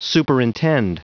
Prononciation du mot : superintend
superintend.wav